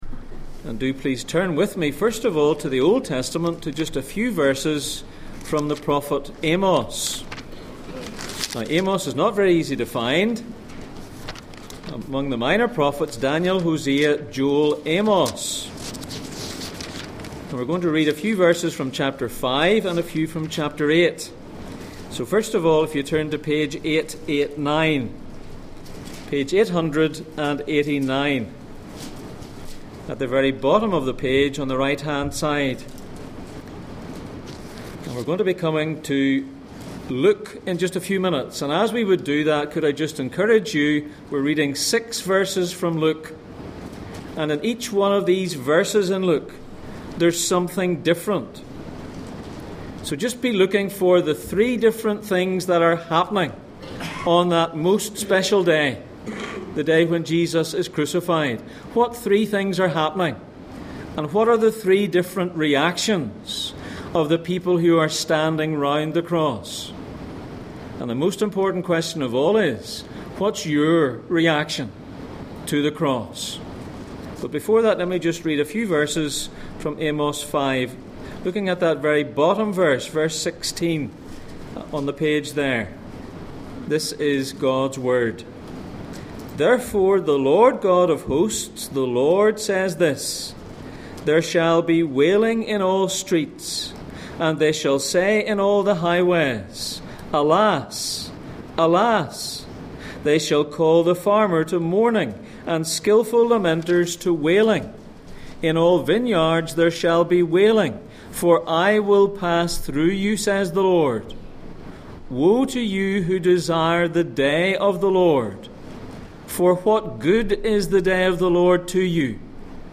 Passage: Luke 23:44-49, Amos 5:16-20, Amos 8:9-10 Service Type: Sunday Morning